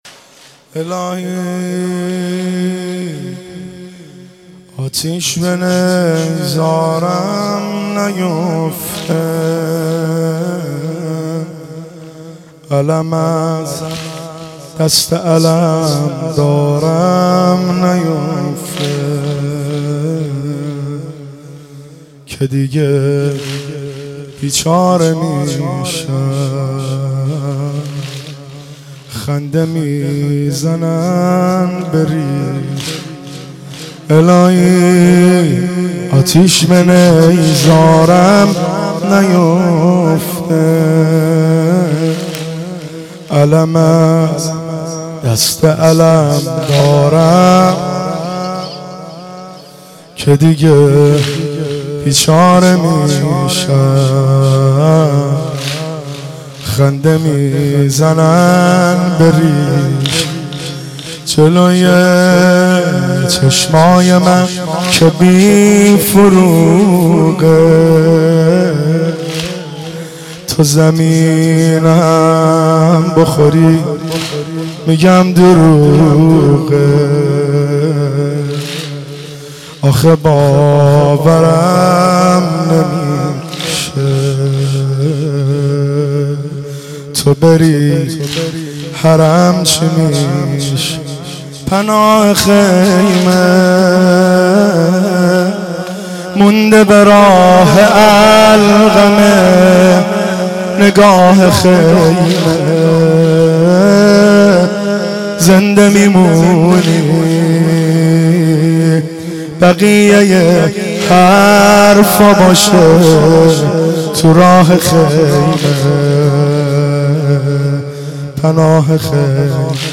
صوت مراسم شب نهم محرم (تاسوعا) ۱۴۳۷ هیئت غریب مدینه امیرکلا ذیلاً می‌آید: